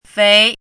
怎么读
féi
fei2.mp3